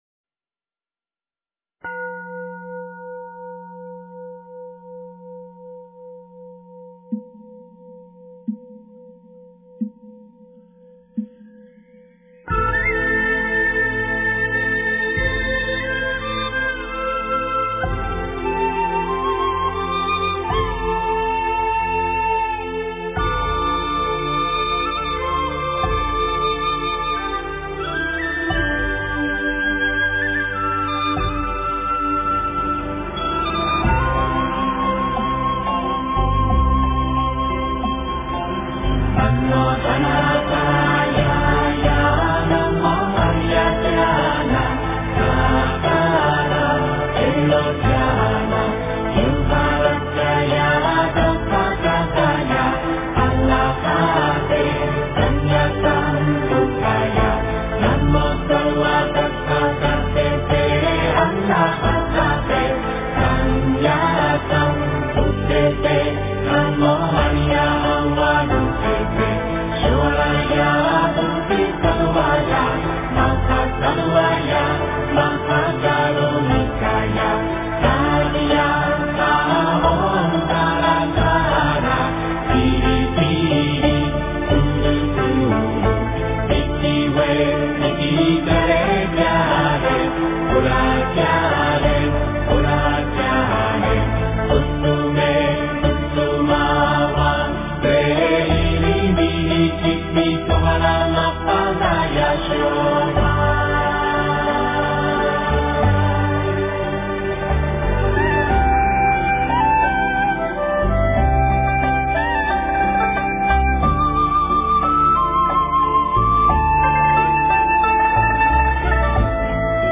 大悲咒 诵经 大悲咒--男女合唱版 点我： 标签: 佛音 诵经 佛教音乐 返回列表 上一篇： 般若波罗蜜多心经-藏文版 下一篇： 大悲咒 相关文章 地藏心咒 地藏心咒--群星...